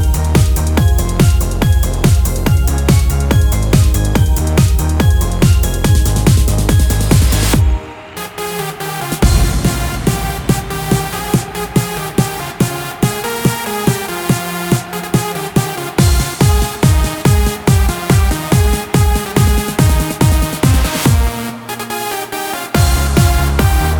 no Backing Vocals Dance 3:20 Buy £1.50